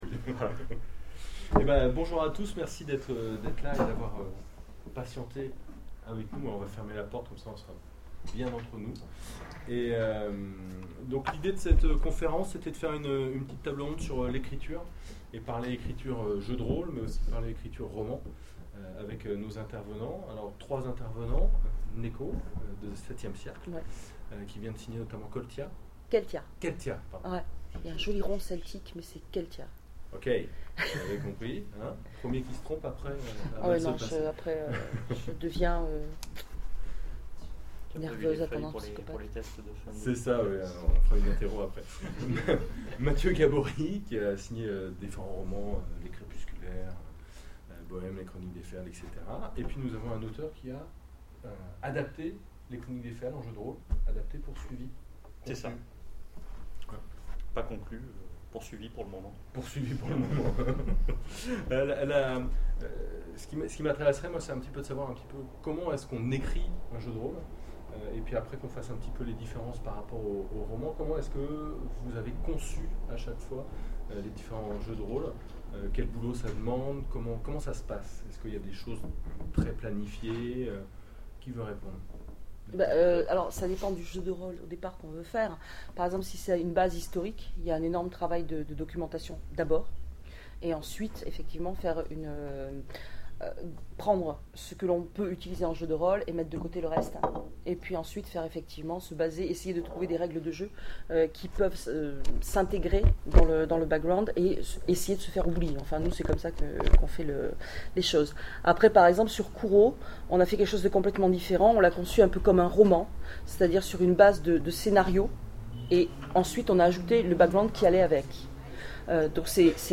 Octogones 2012 : Conférence scénaristes, créateurs de jeux, romanciers et novellistes, l'écriture en question